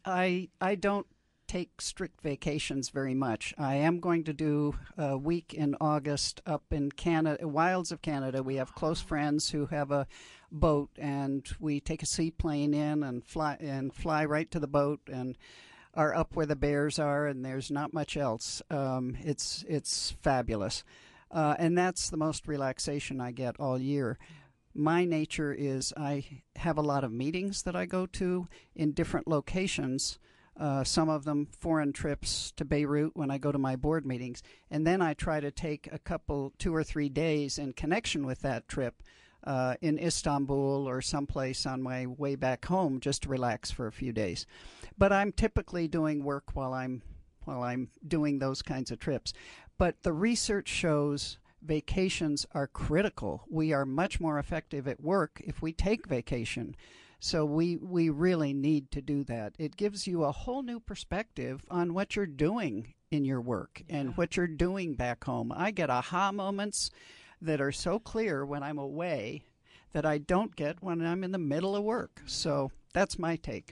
This was one of the very last conversations we had with former SLC Mayor Deedee Corradini on KSL Newsradio. Last July, we talked about the work/life balance with her in a conversation on A Woman's View.